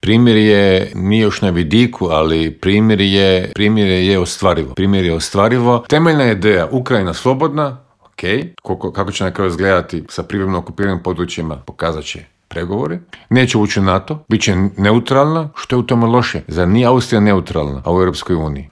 ZAGREB - Bivši ministar vanjskih poslova Miro Kovač gostovao je u Intervjuu Media servisa u kojem se, među ostalim, osvrnuo na rezolucije o Ukrajini koju je Opća skupština Ujedinjenih naroda jučer donijela povodom treće obljetnice rata u toj zemlji.